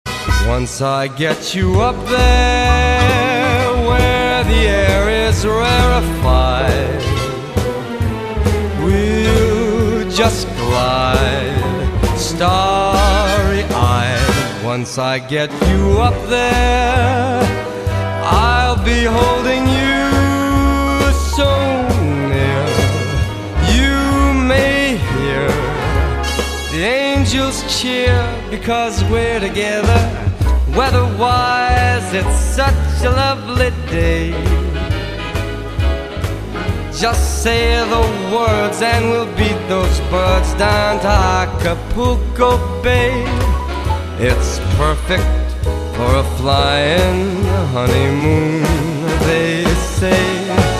欧美歌曲